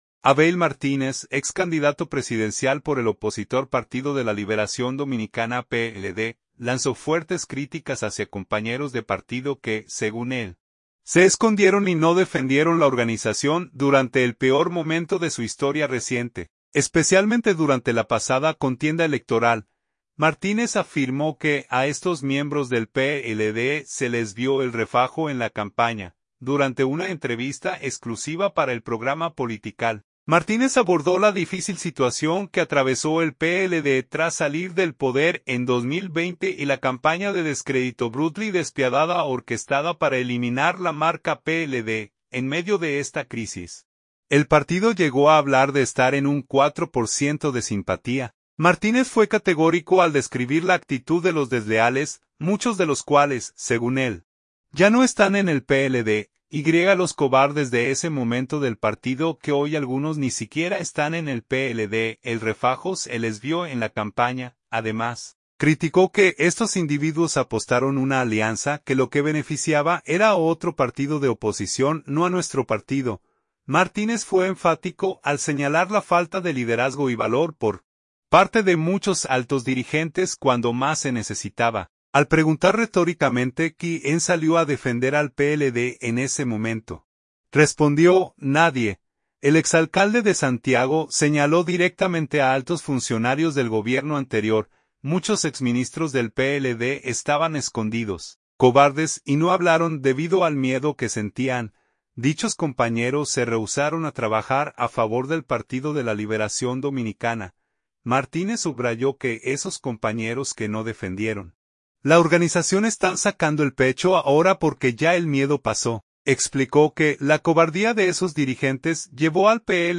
Durante una entrevista exclusiva para el programa Politikal, Martínez abordó la difícil situación que atravesó el PLD tras salir del poder en 2020 y la campaña de descrédito "brutal y despiadada" orquestada para "eliminar la marca PLD".